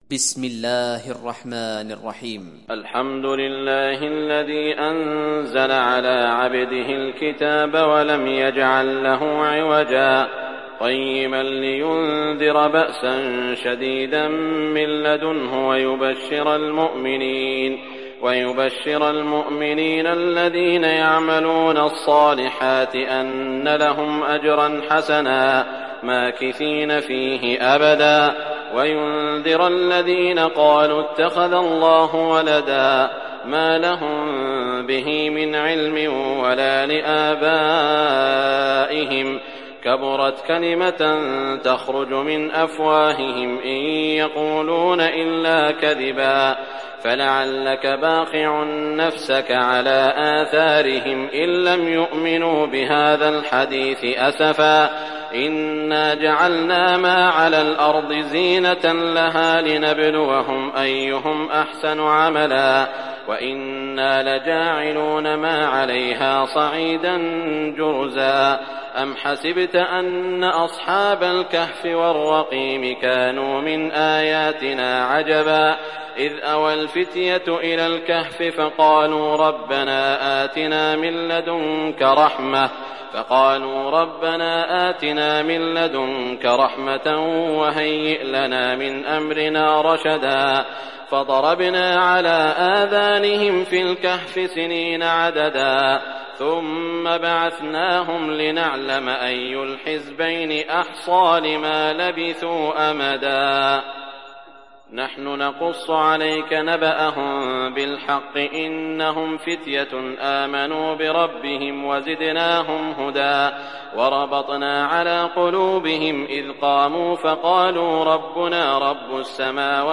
تحميل سورة الكهف mp3 بصوت سعود الشريم برواية حفص عن عاصم, تحميل استماع القرآن الكريم على الجوال mp3 كاملا بروابط مباشرة وسريعة